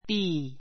bíː